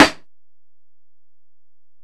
Snare (31).wav